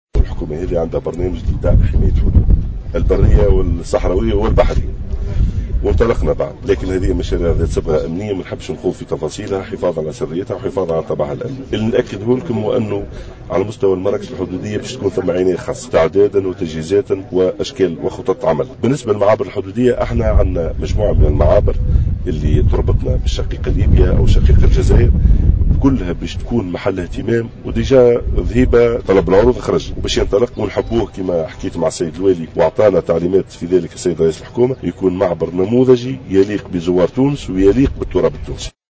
أكد وزير الداخلية محمد ناجم الغرسلي في تصريح لجوهرة أف أم خلال اشرافه اليوم الاثنين 25 ماي 2015 في رمادة من ولاية تطاوين على احياء الذكرى السابعة والخمسين لمعركة رمادة ضد المستعمر الفرنسي أن الحكومة ماضية في سياسة حماية الحدود بكل الوسائل المتاحة والمتطورة.